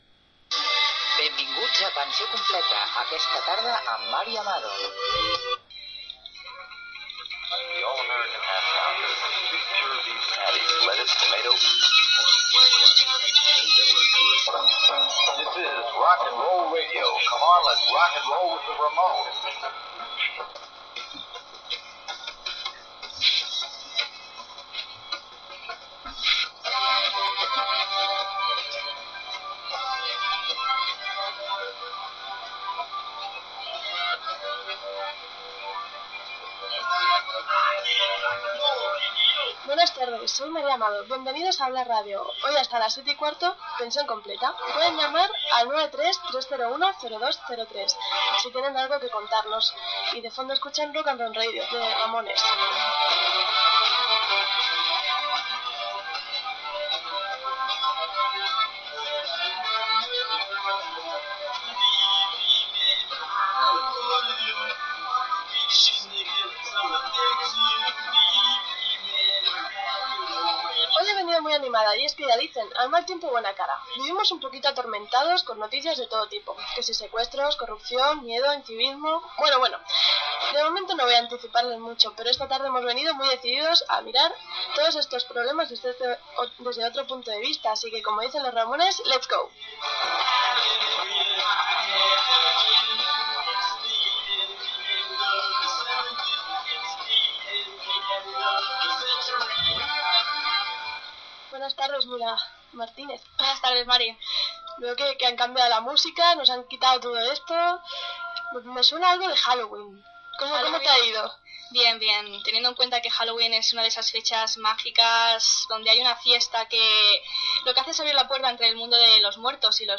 Pensió completa Gènere radiofònic Entreteniment